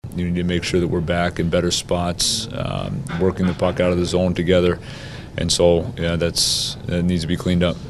Muse says his defensemen weren’t as sharp as they should have been.